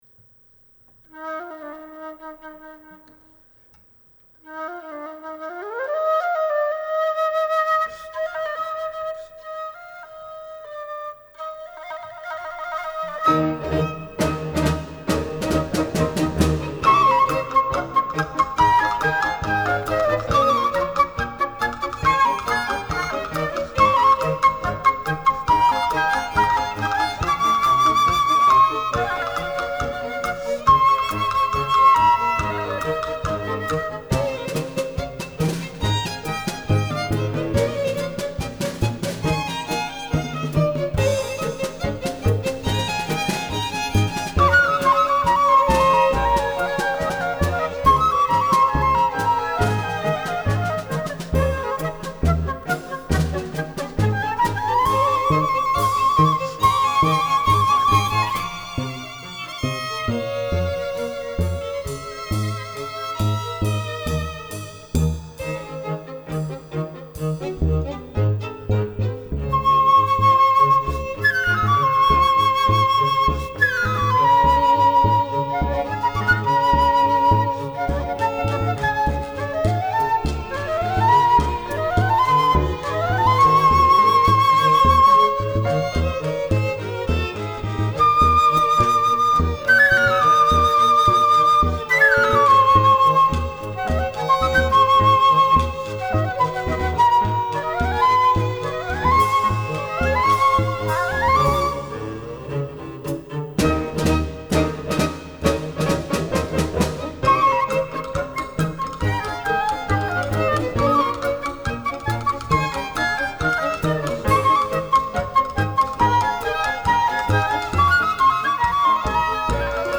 pianoforte
flauto
batteria
contrabbasso